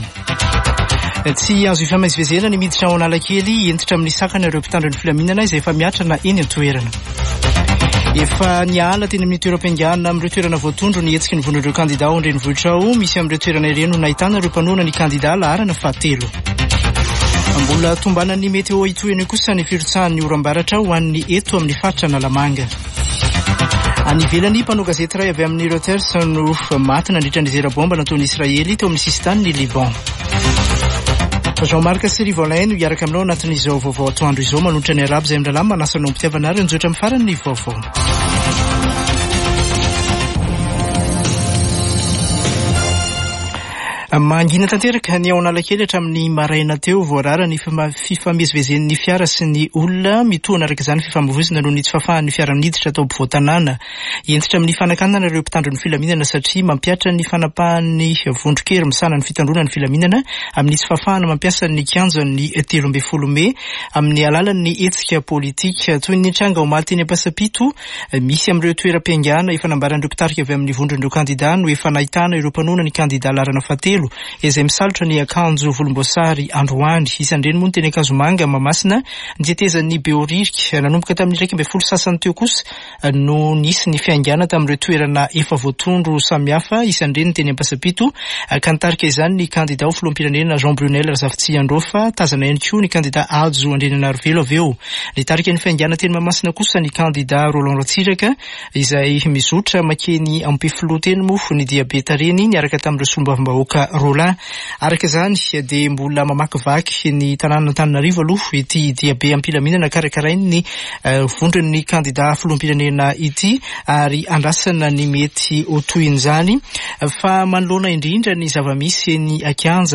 [Vaovao antoandro] Sabotsy 14 ôktôbra 2023